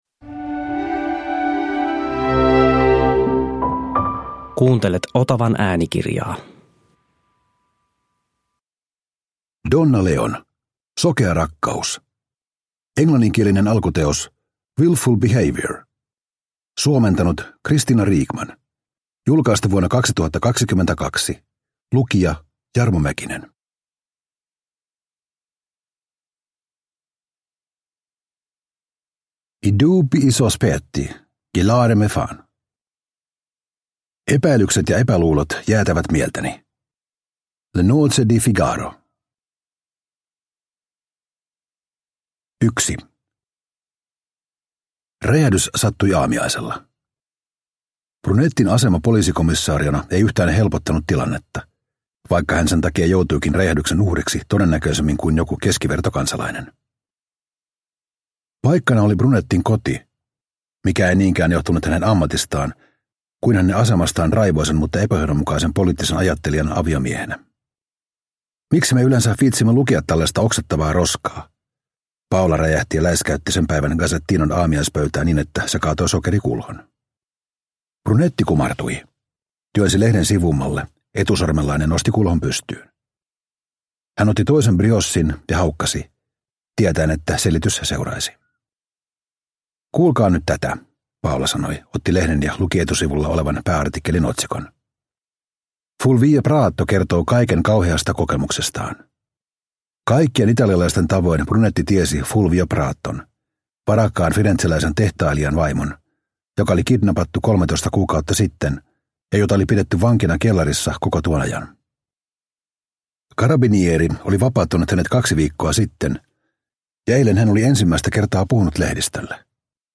Sokea rakkaus – Ljudbok – Laddas ner